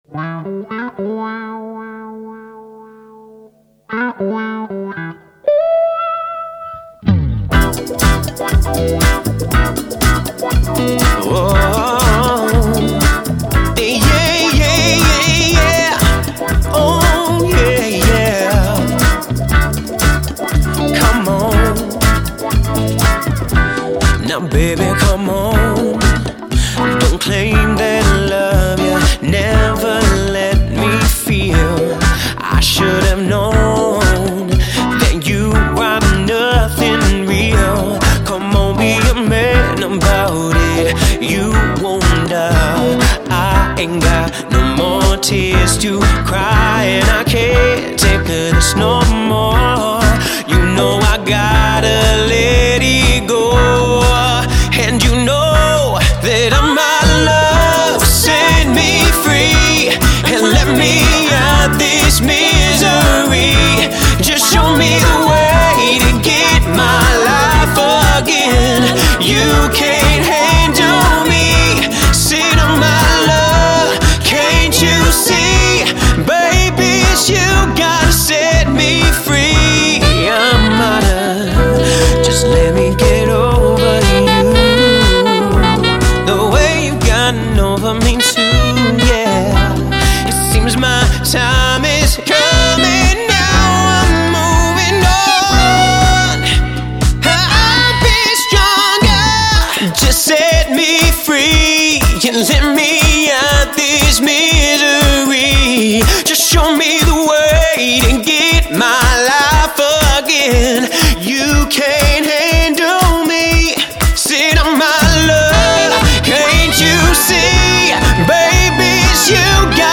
Dual Vocals | Guitar | Duo | Trio | Full Band | DJ Band | MC
Full band